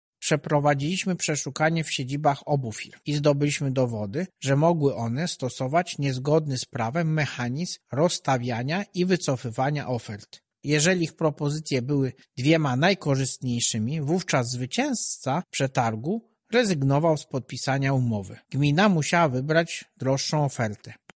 Więcej na ten temat mówi prezes UOKiK – Marek Niechciał :